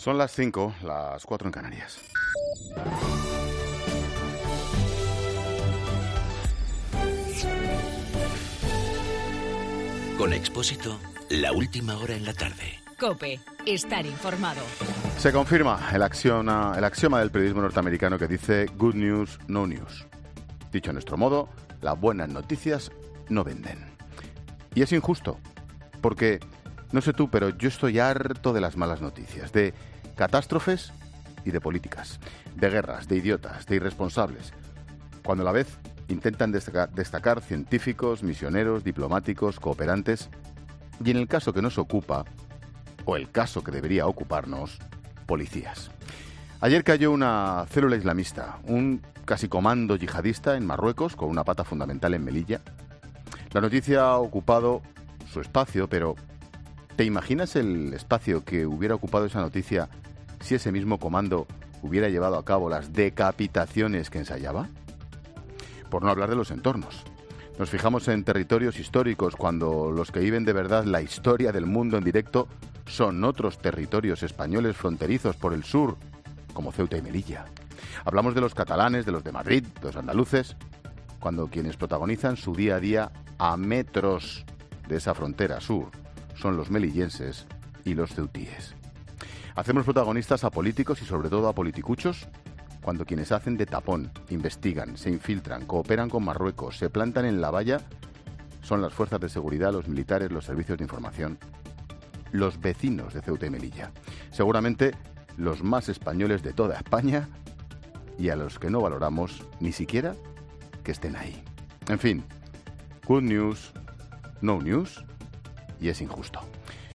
AUDIO: Monólogo de Ángel Expósito de las 17h.